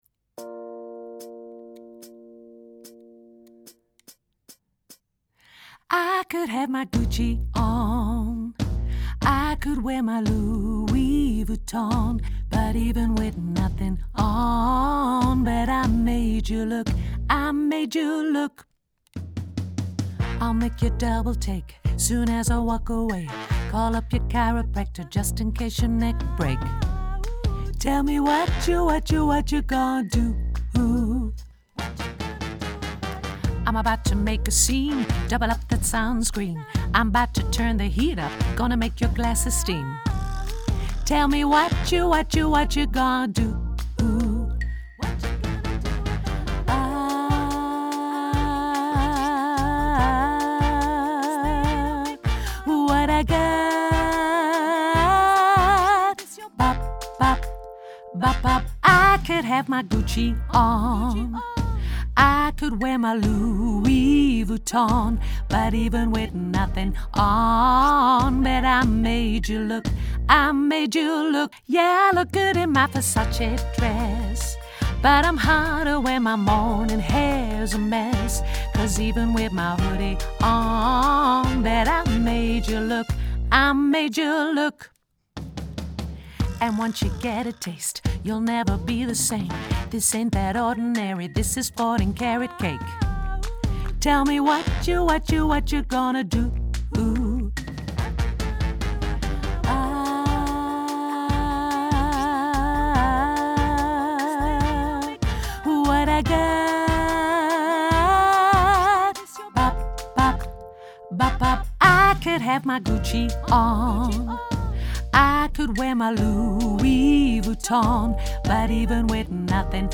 tenor & bas